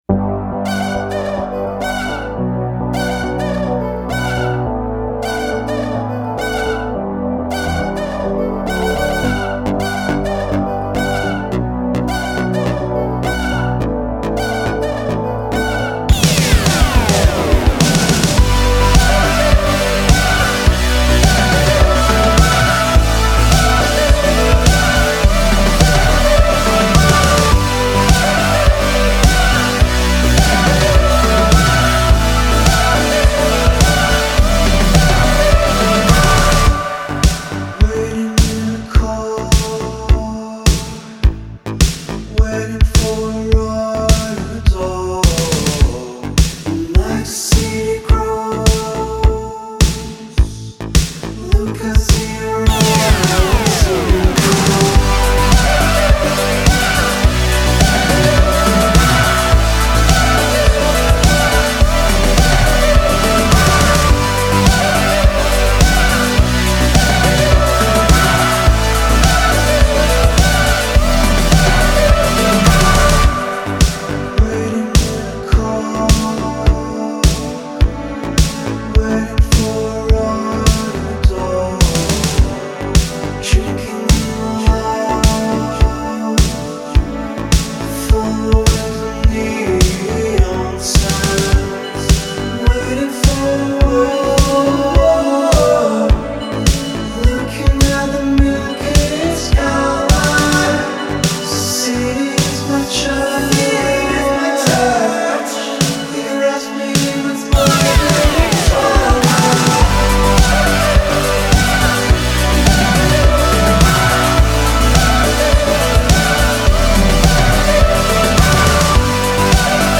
Lots of weird audio wickedness to pet your ear-holes.